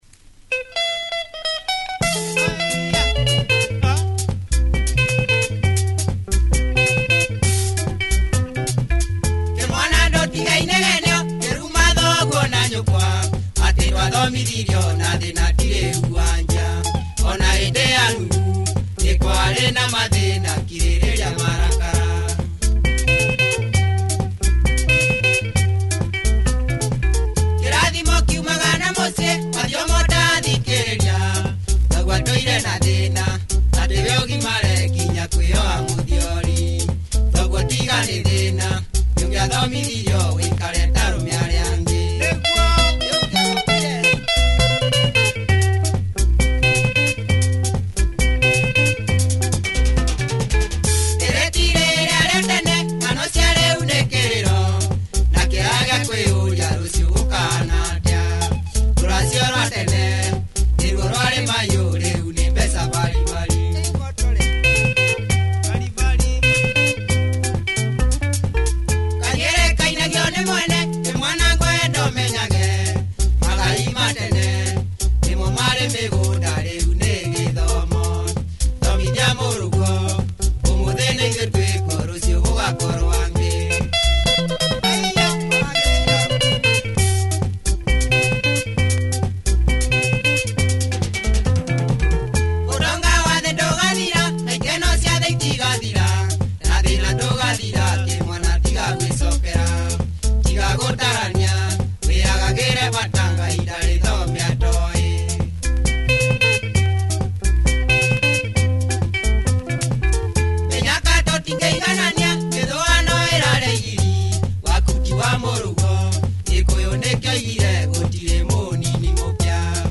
Nice kikuyu benga, check audio for both sides. https